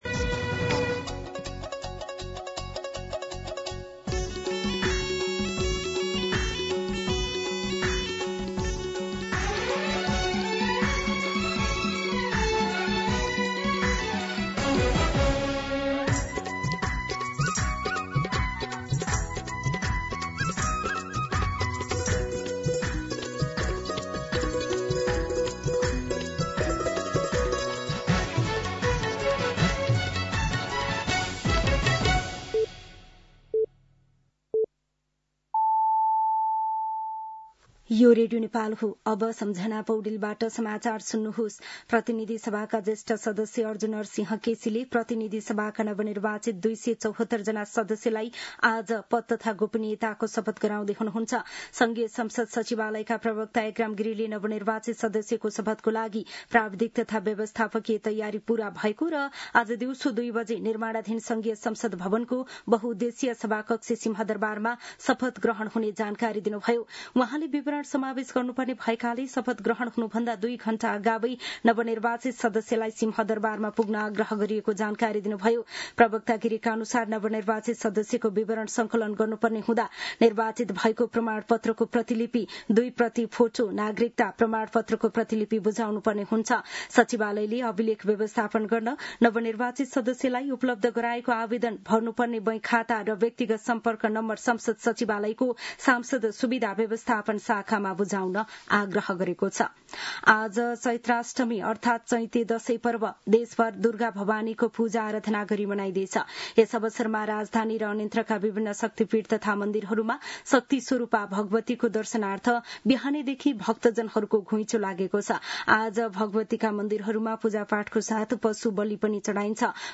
मध्यान्ह १२ बजेको नेपाली समाचार : १२ चैत , २०८२